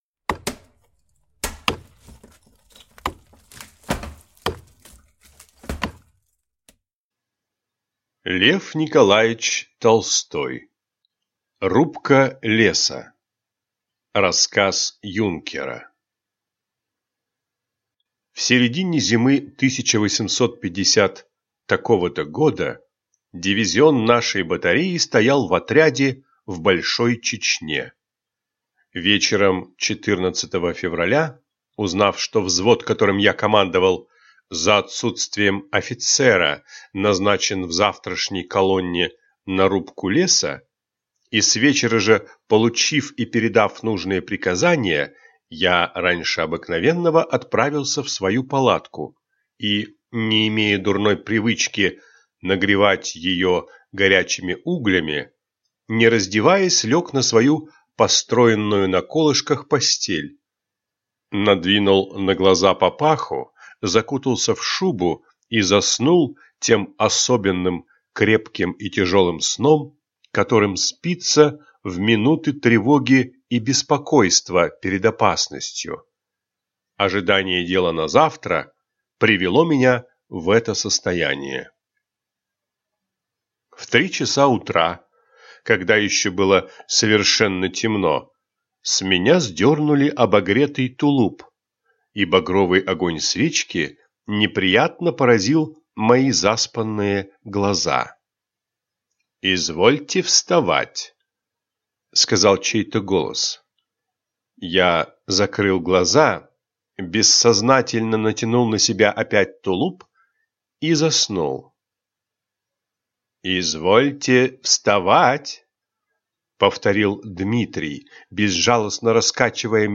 Аудиокнига Рубка леса. Рассказ юнкера | Библиотека аудиокниг